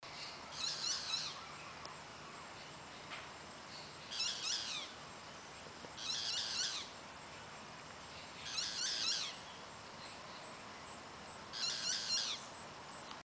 Pitanguá (Megarynchus pitangua)
Nombre en inglés: Boat-billed Flycatcher
Fase de la vida: Adulto
Localidad o área protegida: Puerto Iguazú
Condición: Silvestre
Certeza: Vocalización Grabada